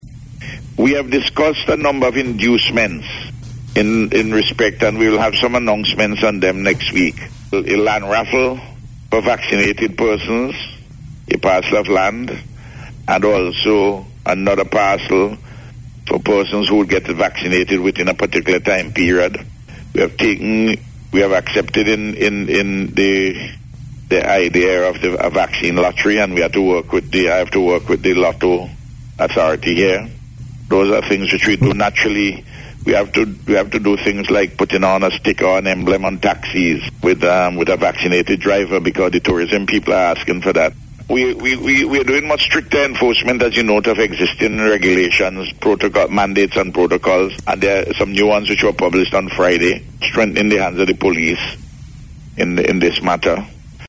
Word of this came from Prime Minister Dr. Ralph Gonsalves as he discussed issues of national interest on radio Sunday.